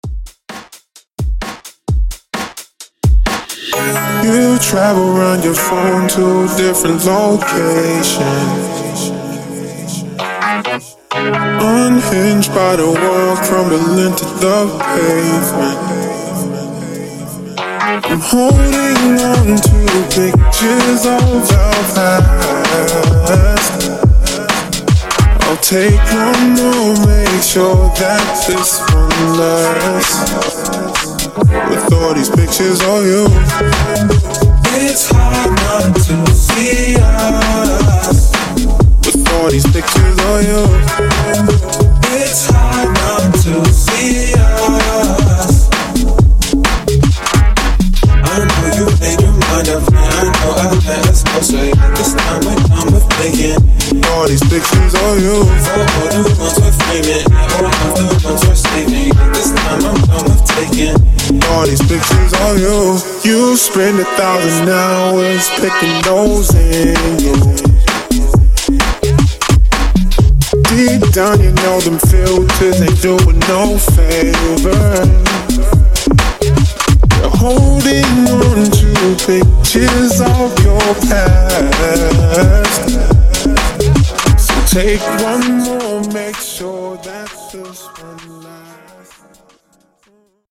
Genres: COUNTRY , RE-DRUM , TOP40
Clean BPM: 68 Time